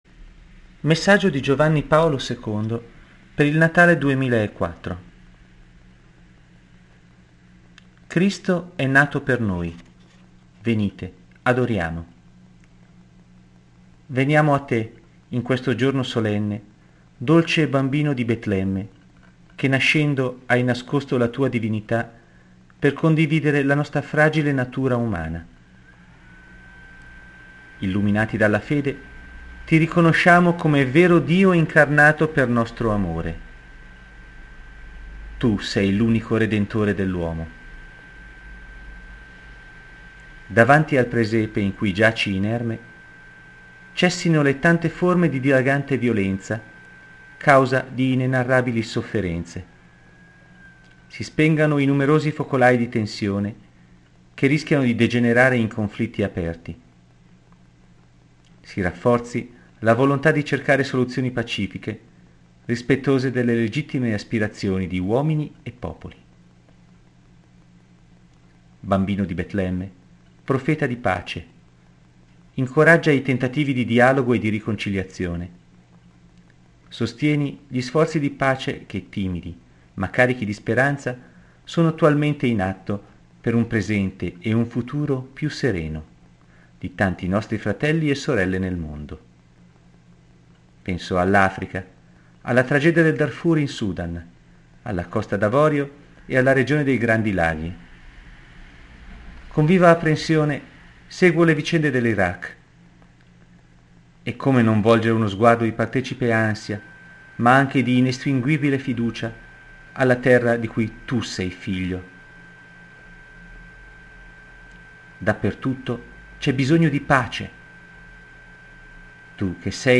Spero vi faccia piacere ascoltarli, anche se chi li legge non ne è degno.